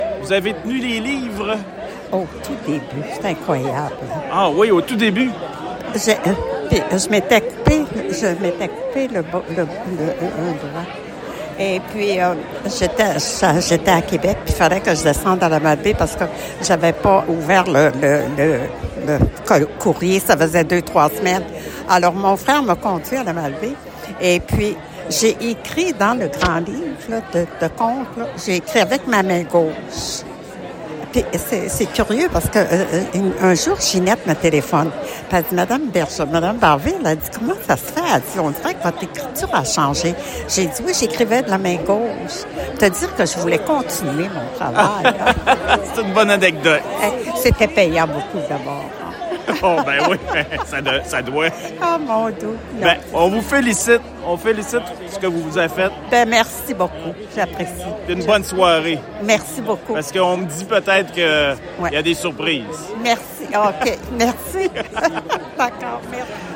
La Fondation de l’Hôpital de La Malbaie a célébré son 40e anniversaire, hier soir, lors d’un cocktail dînatoire au Pavillon Joseph-Rouleau du Domaine Forget.